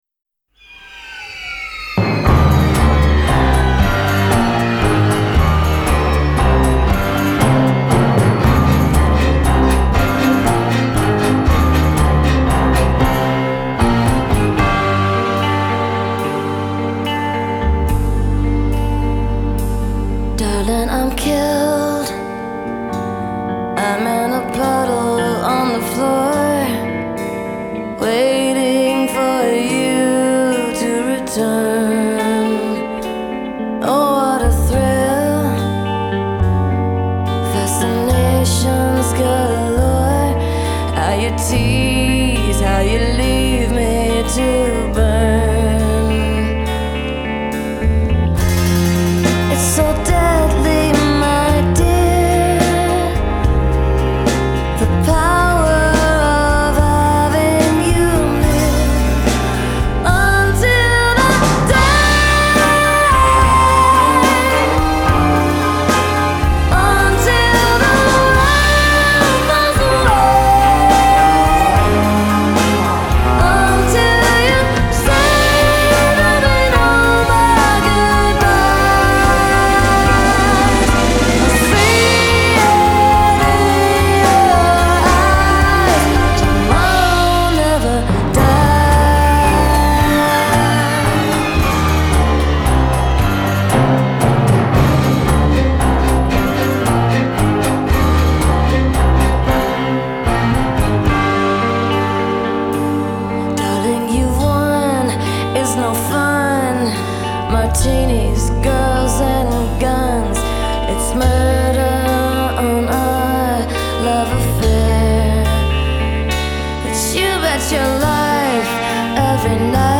Genre : ALternative